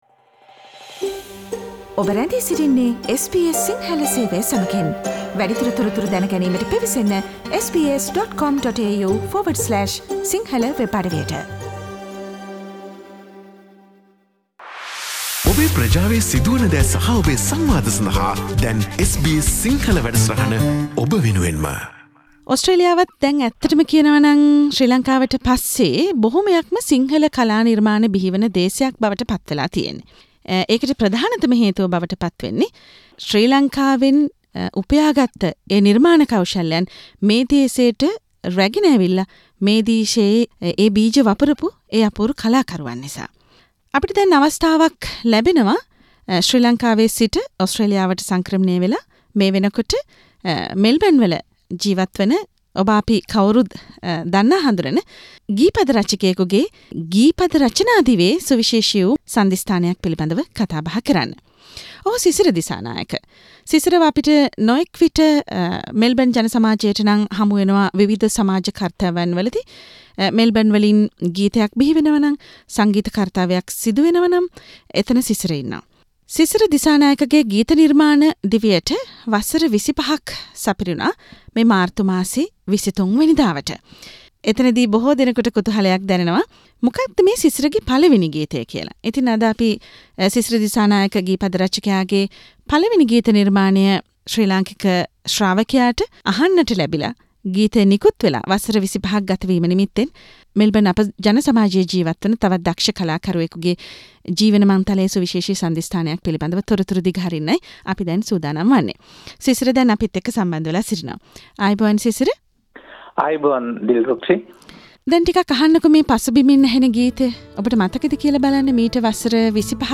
A discussion
SBS Sinhala radio interviewed a renowned Sinhala song writer